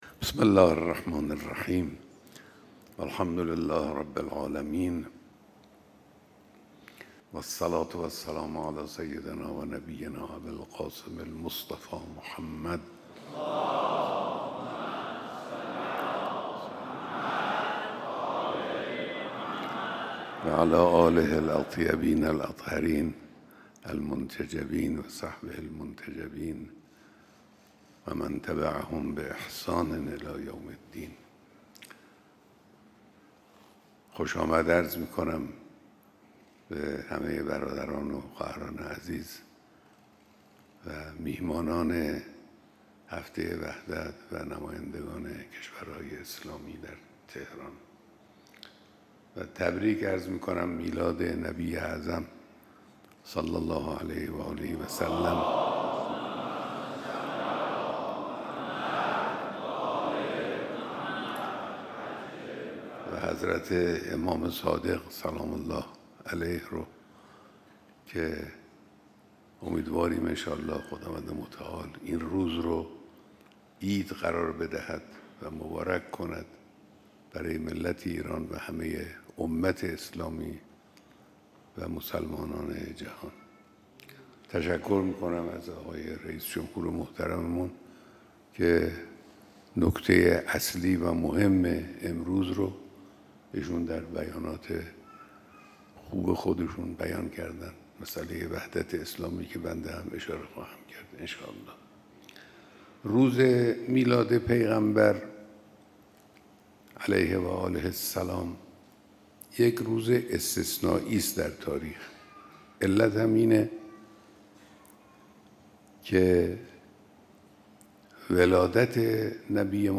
بیانات در دیدار مسئولان نظام، سفرای کشورهای اسلامی و مهمانان کنفرانس وحدت